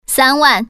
Index of /hunan_feature2/update/12623/res/sfx/common_woman/